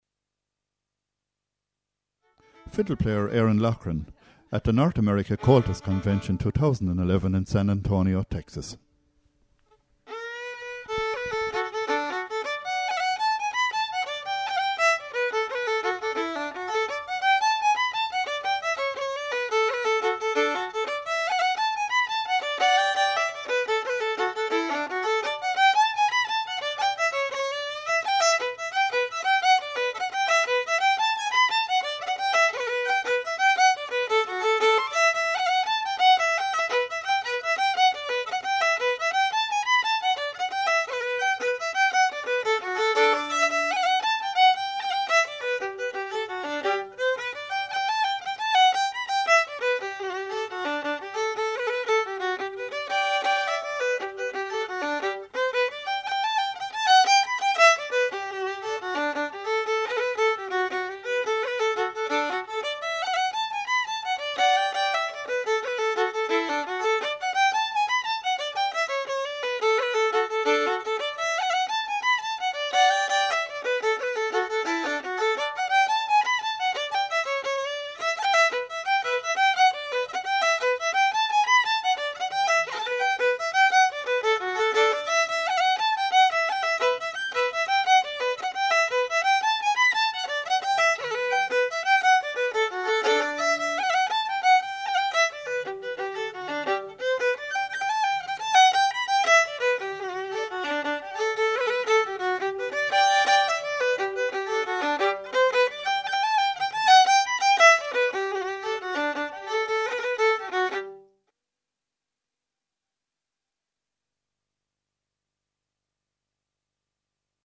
Recorded at the North American Comhaltas Convention in San Antonio, Texas in March 2011
Convention, Solo, Fiddle, Jig, ComhaltasLive